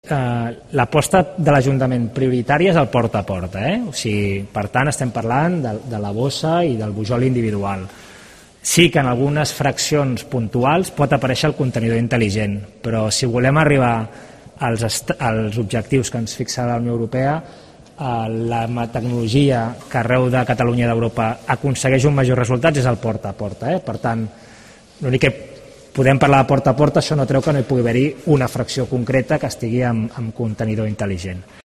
El regidor del Ayuntamiento de Barcelona, Eloi Badia explica que